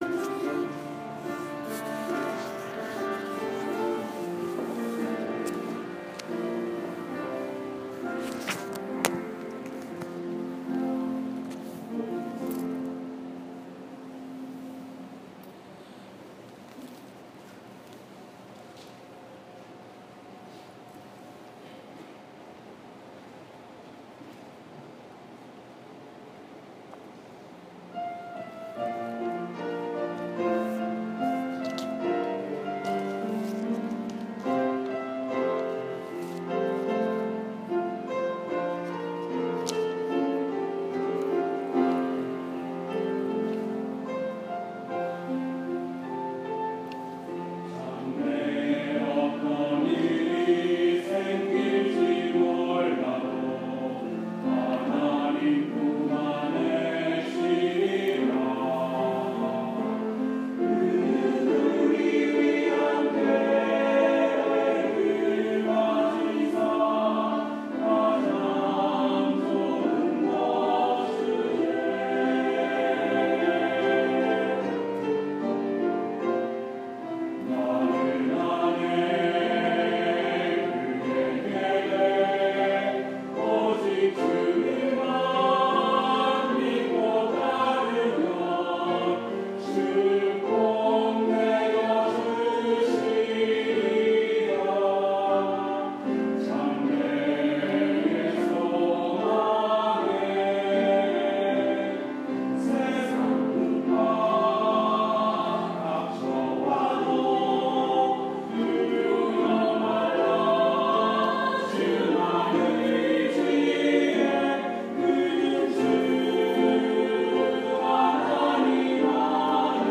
5월 29일 주일 찬양대 찬양(나는 아네 그의 계획, Cindy Berry곡)